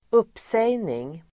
Uttal: [²'up:sej:ning el. -sä:gning]